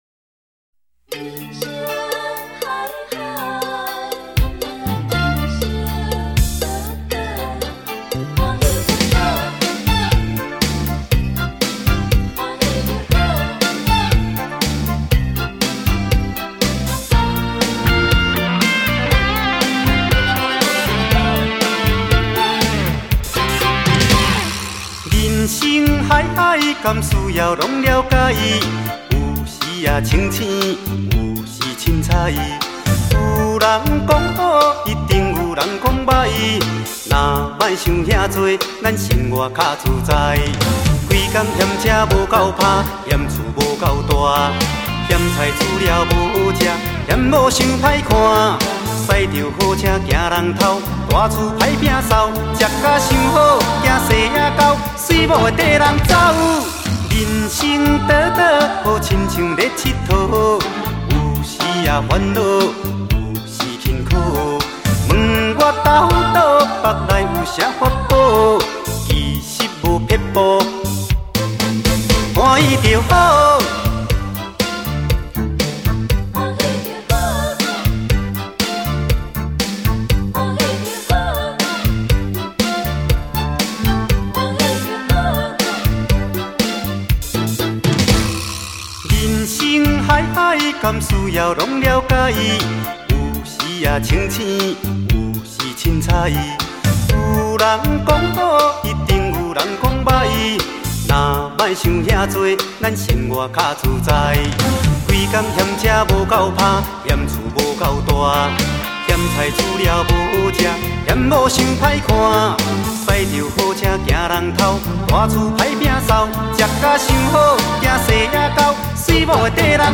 极具代表性的闽南语歌曲